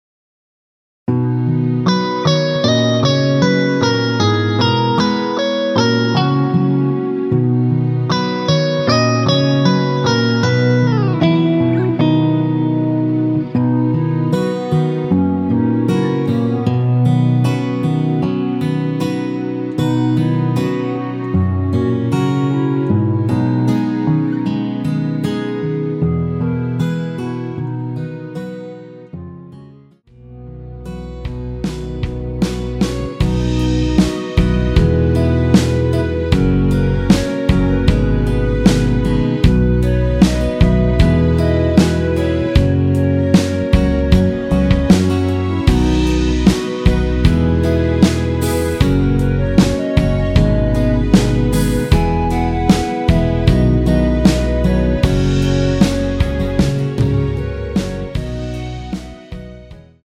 원키에서(-2)내린 멜로디 포함된 MR입니다.
◈ 곡명 옆 (-1)은 반음 내림, (+1)은 반음 올림 입니다.
멜로디 MR이란
앞부분30초, 뒷부분30초씩 편집해서 올려 드리고 있습니다.
중간에 음이 끈어지고 다시 나오는 이유는